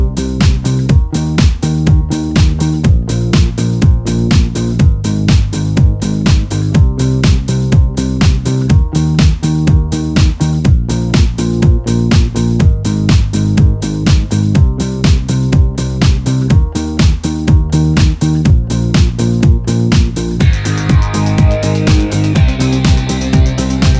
No Intro Voiceover Pop (1990s) 3:41 Buy £1.50